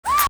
1da ahhh.wav